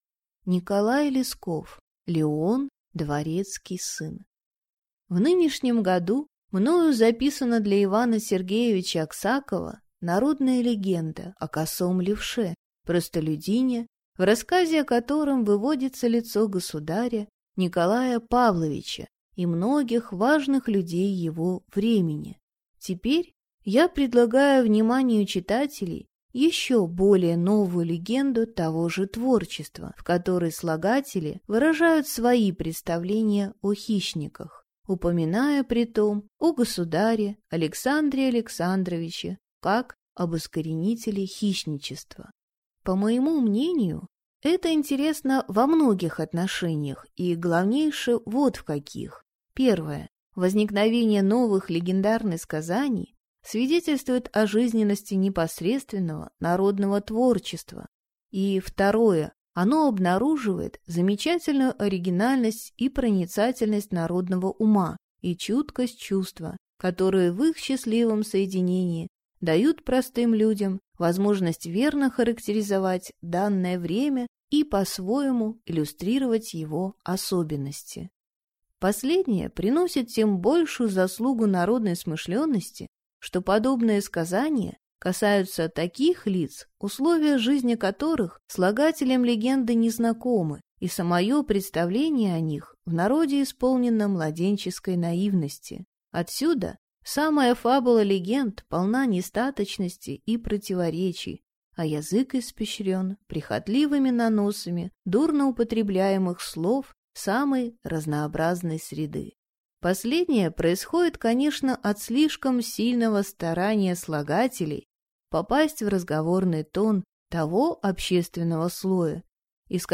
Аудиокнига Леон дворецкий сын | Библиотека аудиокниг